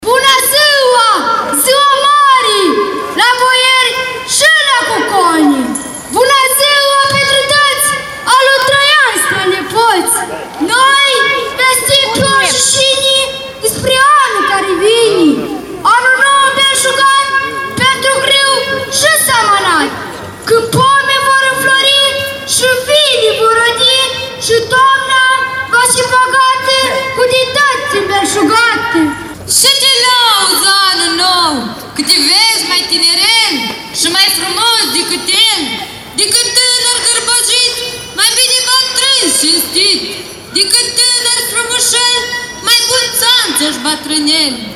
Apoi urmează pe esplanada Casei Culturii spectacole de datini și obiceiuri de iarnă, cu participarea invitaților din Cernăuți și Republica Moldova.